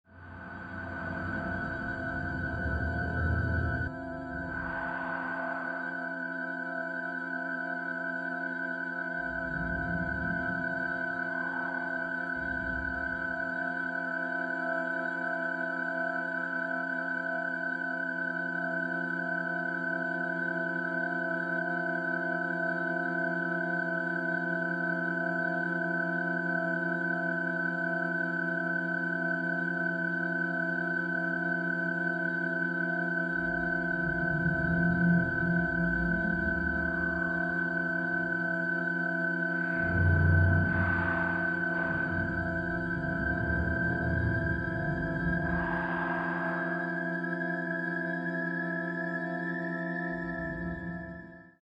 Category: deep ambient, experimental, soundscapes